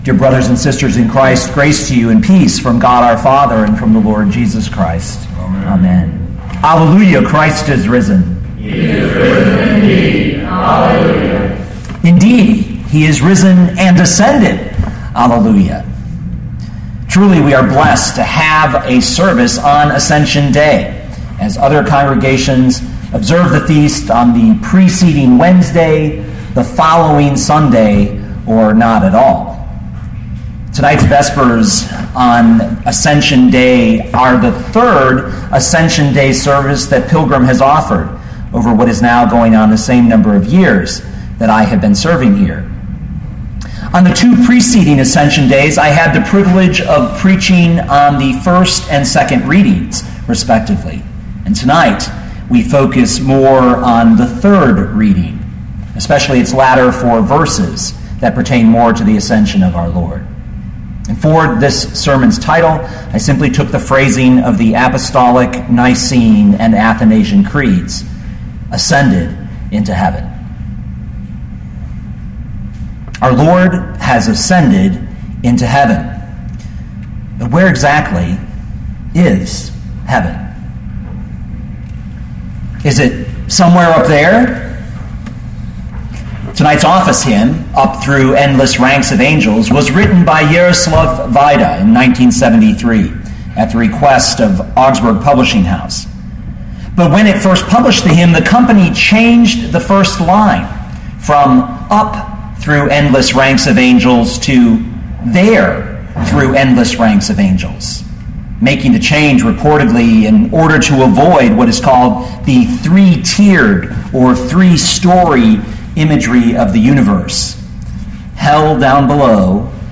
2014 Luke 24:50-53 Listen to the sermon with the player below, or, download the audio.
Truly, we are blessed to have a service on Ascension Day , as other congregations observe the feast on the preceding Wednesday, the following Sunday, or not at all.